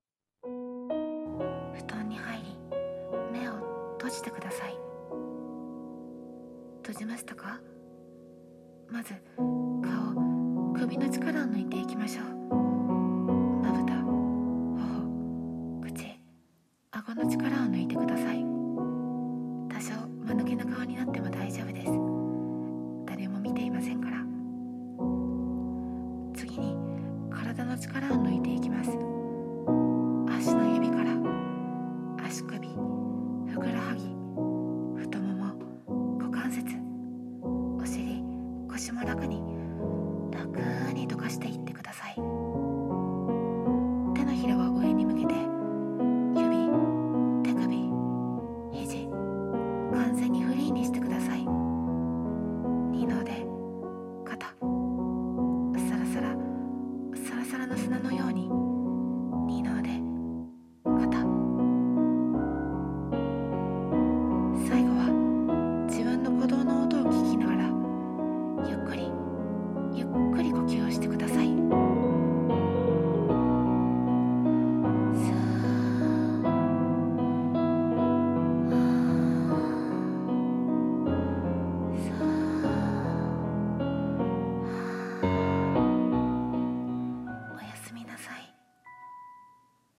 私的睡眠導入音声